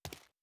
01_硬地面_3.wav